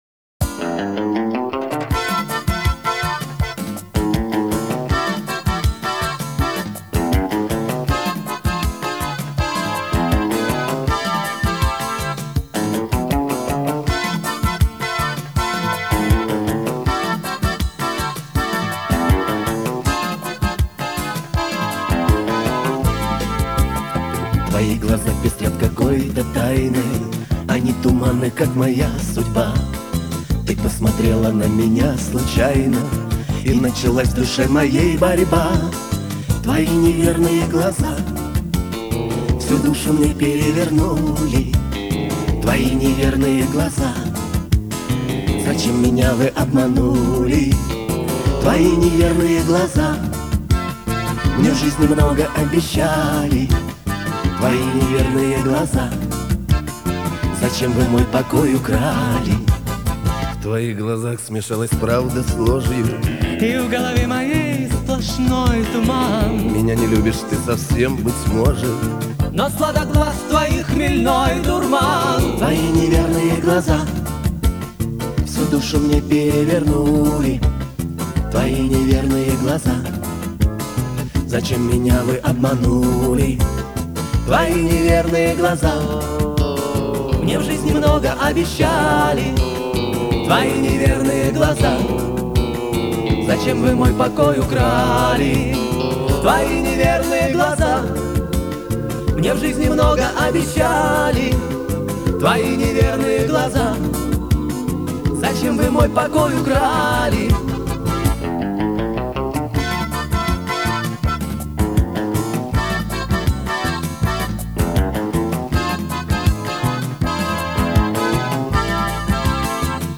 Качество приличное, но хотелось бы 320Kbps студийку.
Да, качество хорошее а реальный битрейт  192 Спасибо.